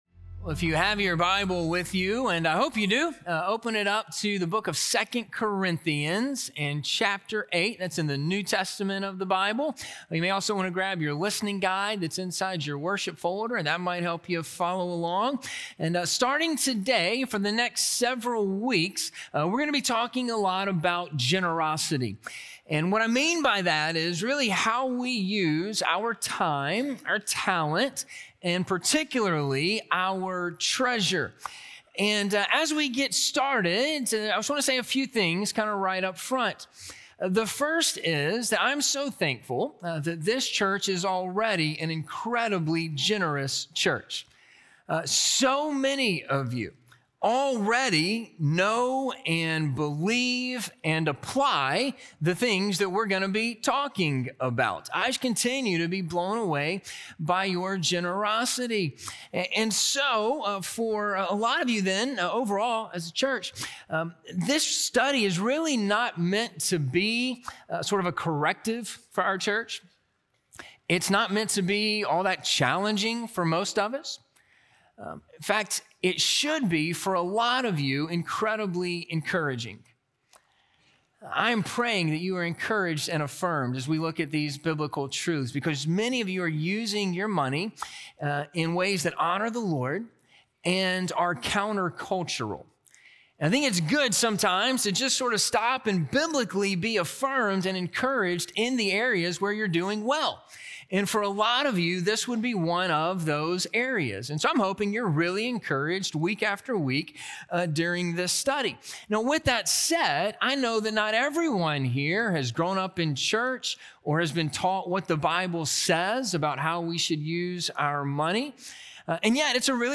A Generous Heart, Part 1 - Sermon - Ingleside Baptist Church